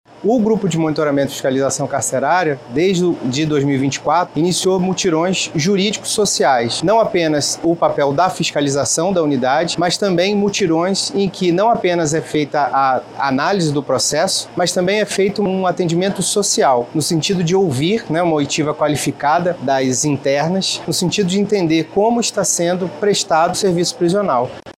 A ação é coordenada pelo Grupo de Monitoramento e Fiscalização do Sistema Carcerário e Socioeducativo do Tribunal de Justiça do Estado do Amazonas (GMF/TJAM). Segundo o juiz, João Gabriel, a iniciativa tem a meta de atender todas as pessoas privadas de liberdade da unidade em dois dias de mutirão.